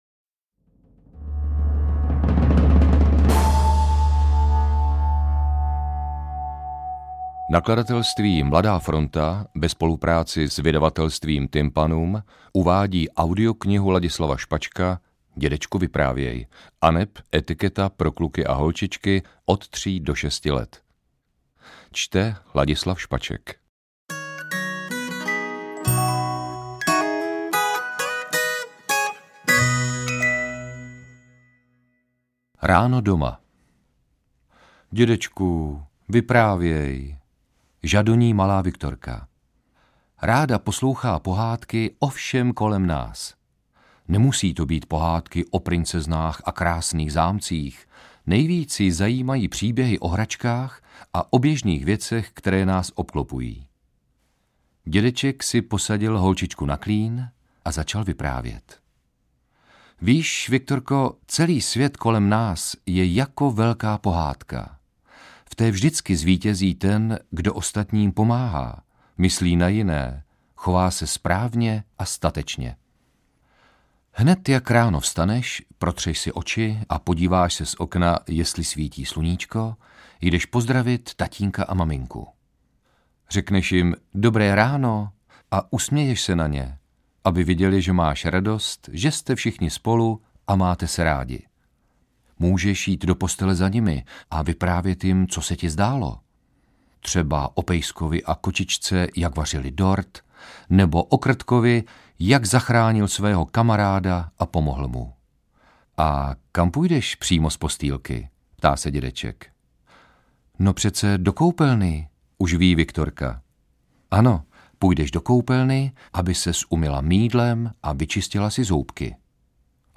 Interpret:  Ladislav Špaček
AudioKniha ke stažení, 13 x mp3, délka 55 min., velikost 50,2 MB, česky